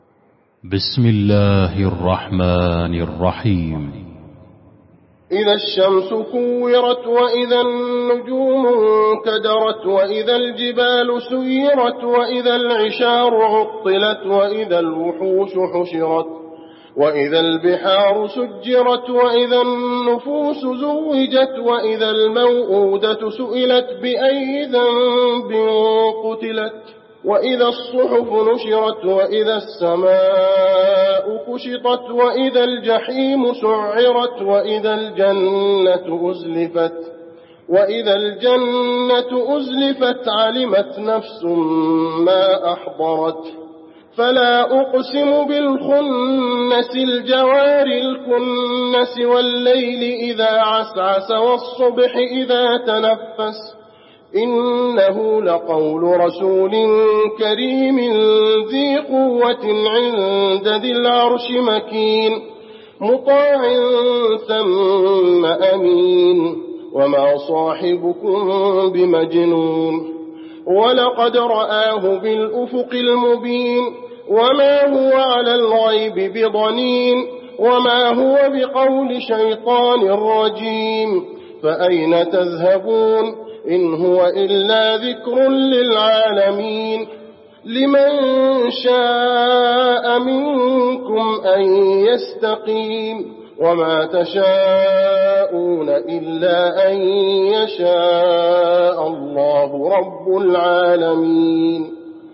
المكان: المسجد النبوي التكوير